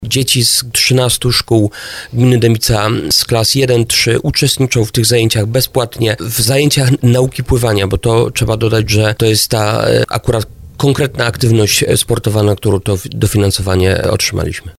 Mówił o tym w audycji Słowo za Słowo wójt Adam Pieniążek.